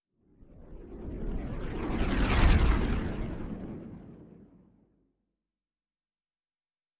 Distant Ship Pass By 4_4.wav